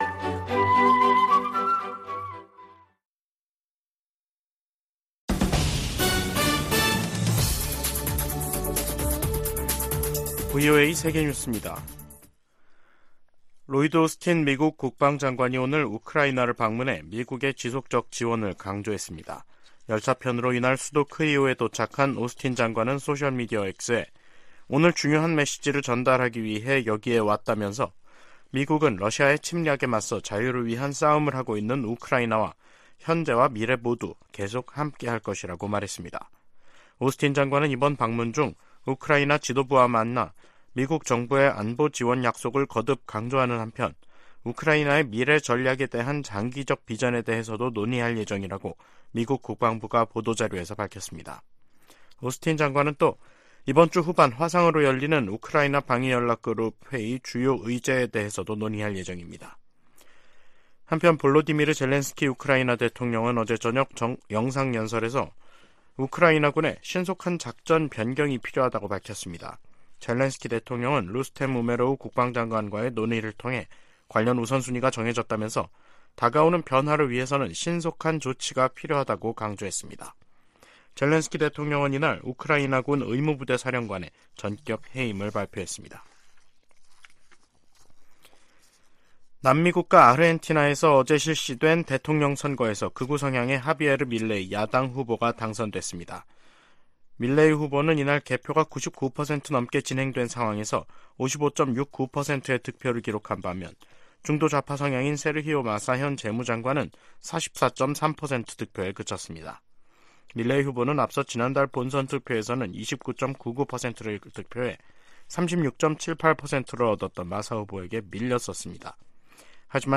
VOA 한국어 간판 뉴스 프로그램 '뉴스 투데이', 2023년 11월 20일 2부 방송입니다. 아시아태평양경제협력체(APEC) 21개 회원국들이 다자무역의 중요성을 강조하는 ‘2023 골든게이트 선언’을 채택했습니다. 한국 합동참모본부는 북한에 3차 군사정찰위성 발사 준비를 중단하라는 경고성명을 냈습니다. 미중 정상회담으로 두 나라간 긴장이 다소 완화된 것은 한반도 정세 안정에도 긍정적이라고 전문가들이 진단했습니다.